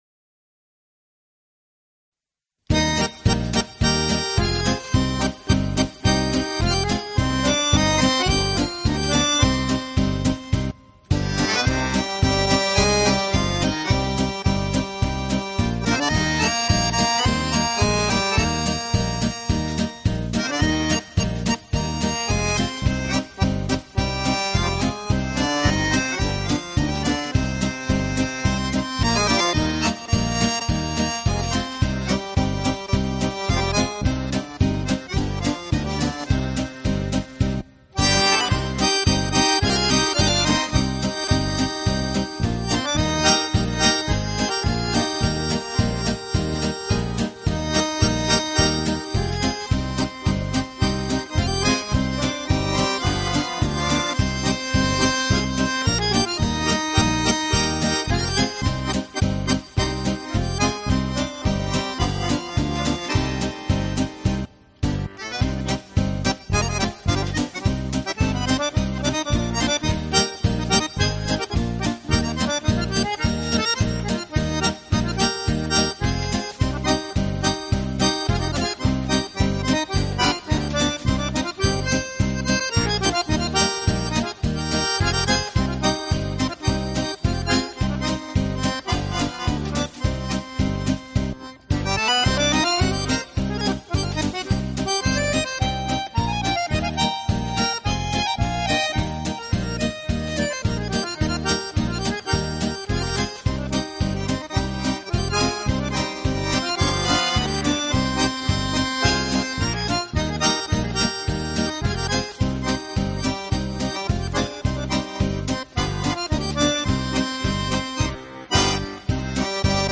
同时其录音又极其细致、干净、层次分明，配器简洁明了，是近